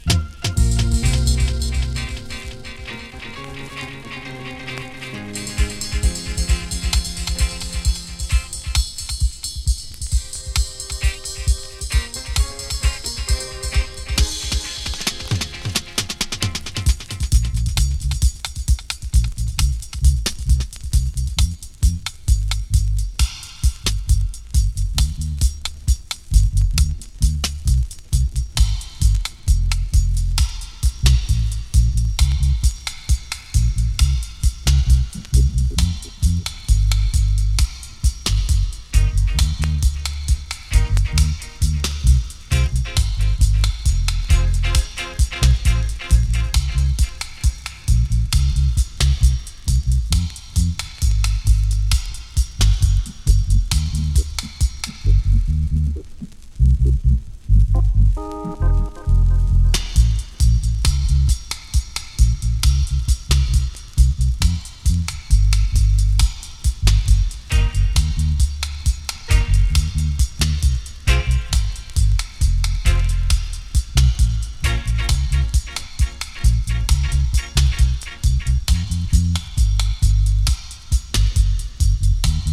dub version